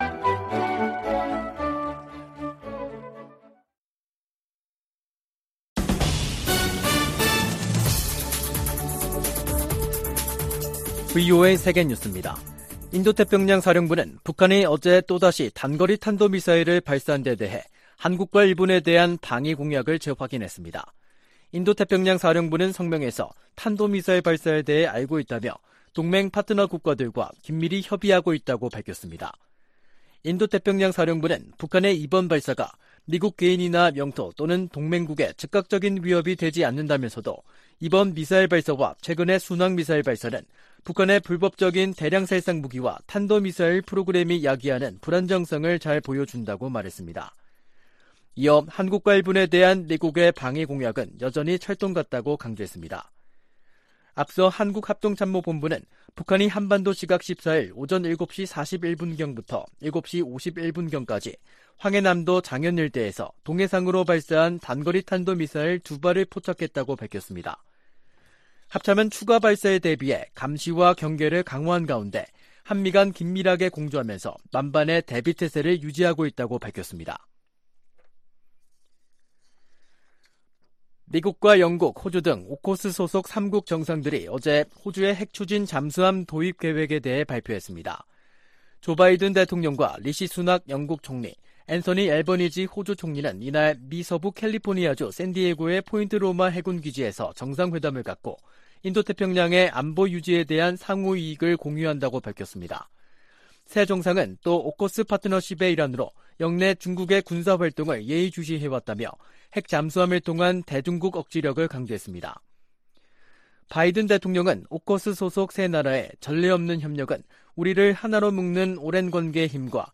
VOA 한국어 간판 뉴스 프로그램 '뉴스 투데이', 2023년 3월 14일 2부 방송입니다. 미국과 한국이 ‘자유의 방패’ 연합훈련을 실시하고 있는 가운데 북한은 미사일 도발을 이어가고 있습니다. 백악관은 한반도 안정을 저해하는 북한의 어떤 행동도 용납하지 않을 것이라고 경고했습니다. 한국을 주요 7개국(G7)에 포함시키는 방안을 추진해야 한다는 제안이 나온 데 전직 주한 미국대사들은 환영의 입장을 나타냈습니다.